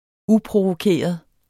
Udtale [ ˈupʁovoˌkeˀʌð ]